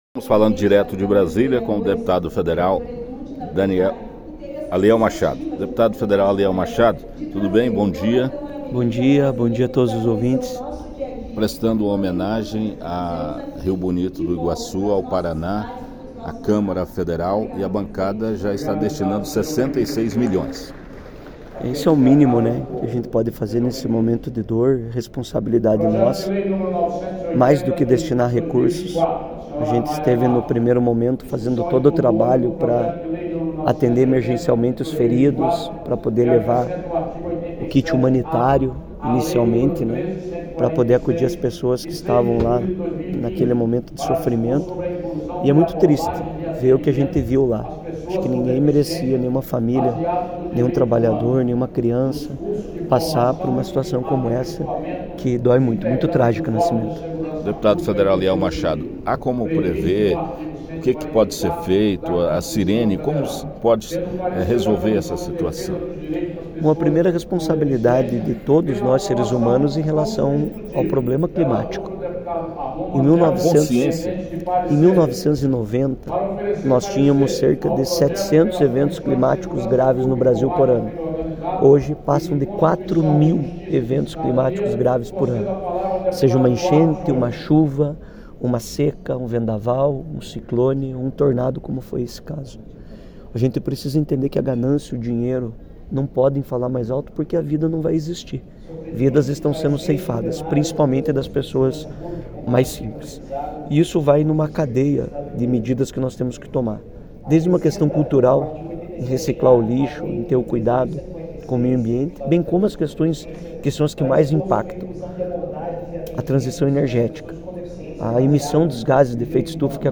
entrevistou o parlamentar.